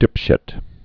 (dĭpshĭt) Vulgar Slang